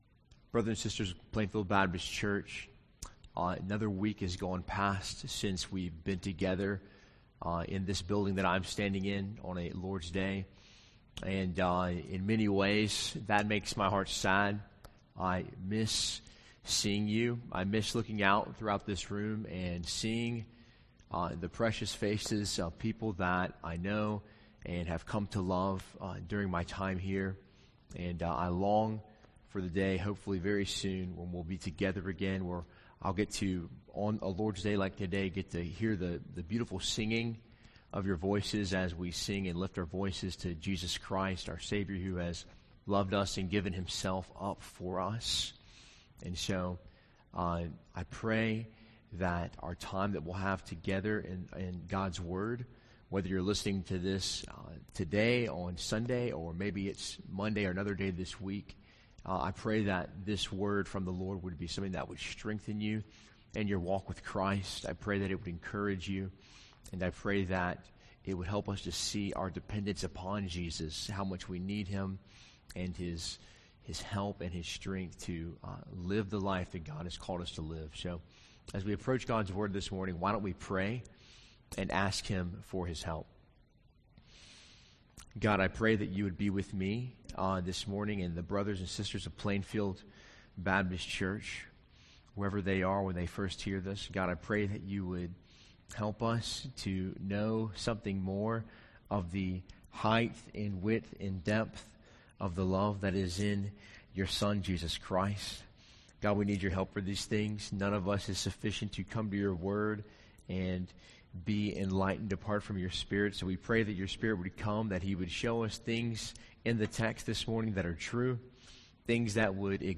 Sermon March 29, 2020